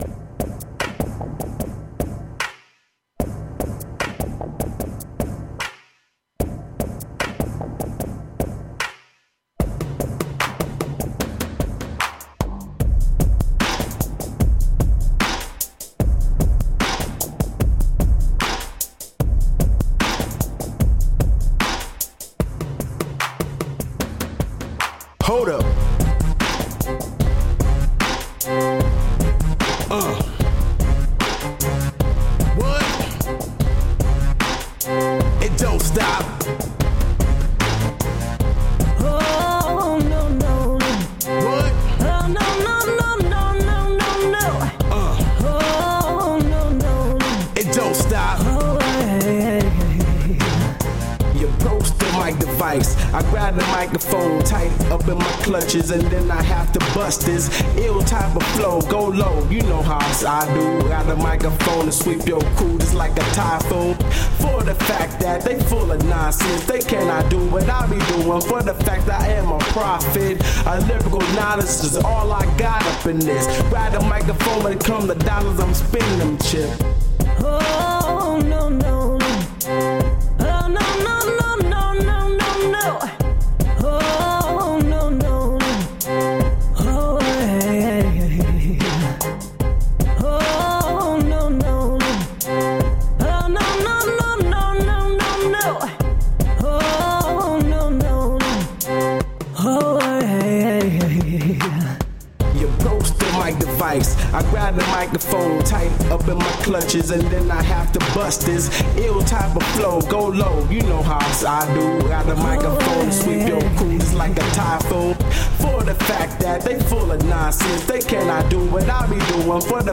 HipHop Audio Demo.mp3